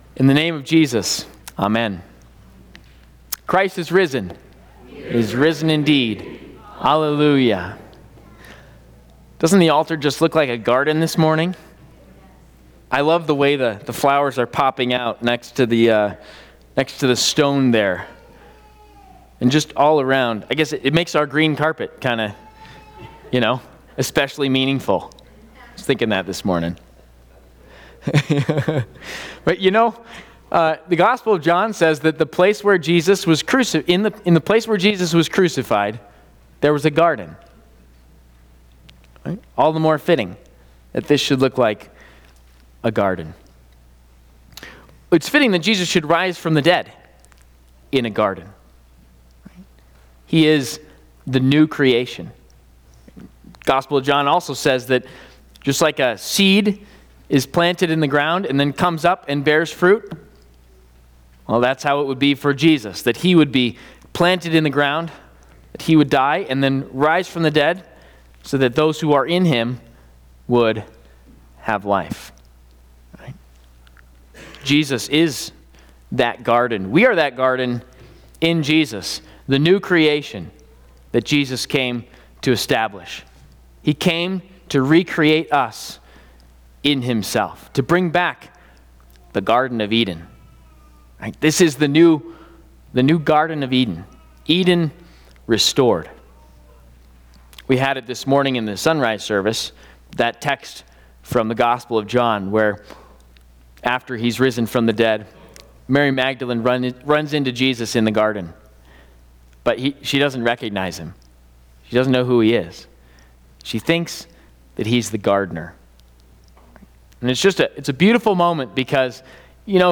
Easter Service He is Risen!